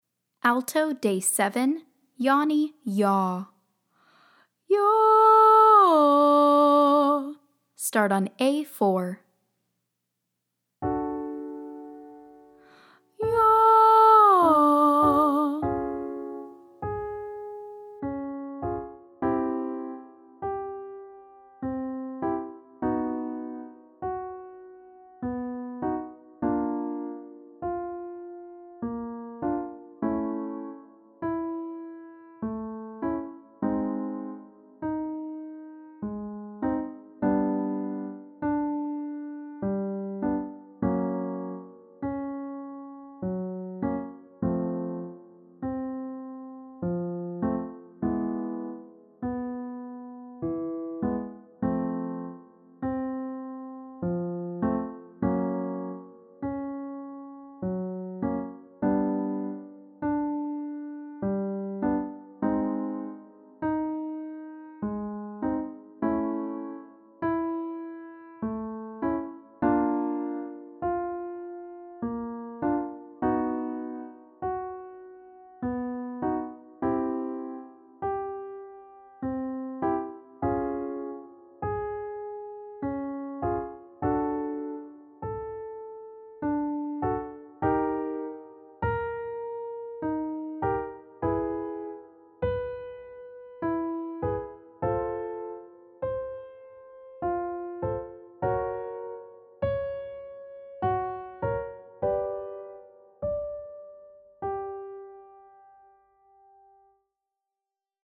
Day 7 - Alto - Yawny YAH